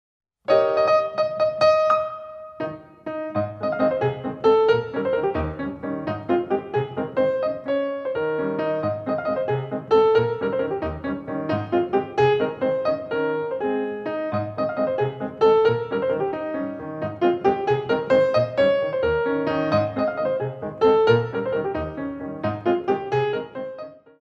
Petit Allegro